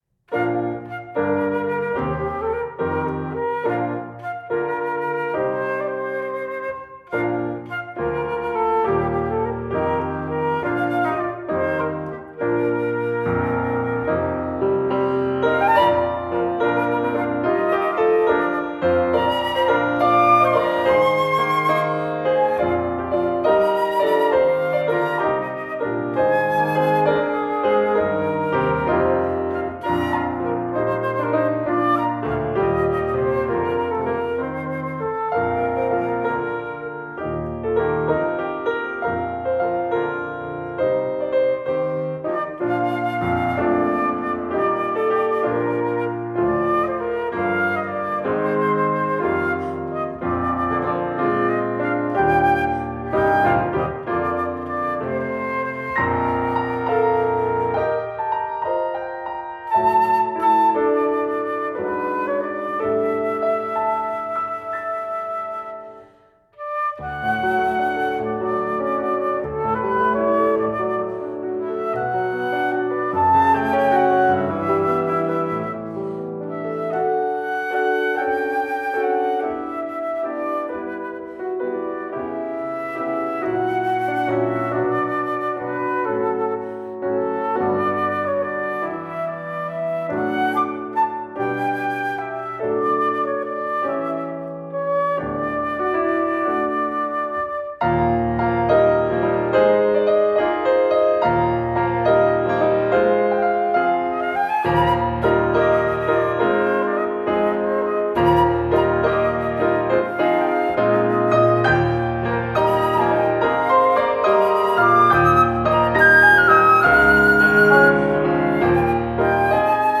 original flute and piano arrangements of familiar hymns